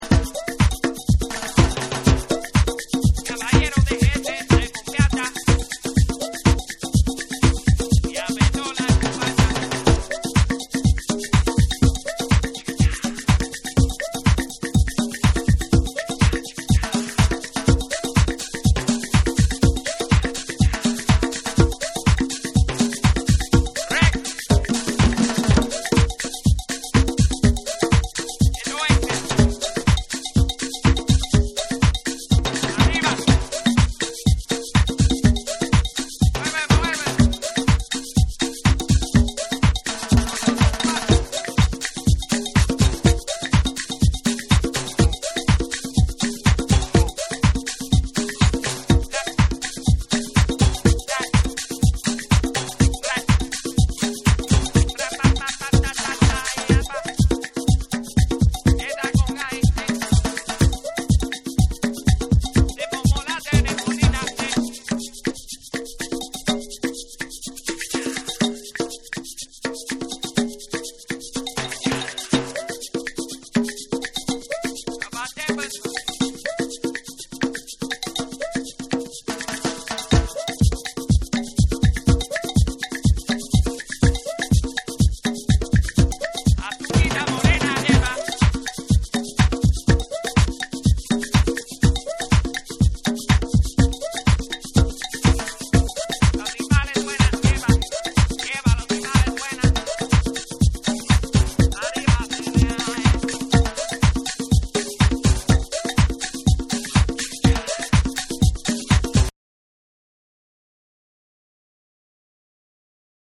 アフロ・キューバンのリズムに、南国系のロウファイなエレピやエモーショナルな鍵盤が絡み展開するトロピアカル・ハウス
TECHNO & HOUSE / ORGANIC GROOVE / BACK TO BASIC